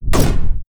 IMPACT_Generic_18_mono.wav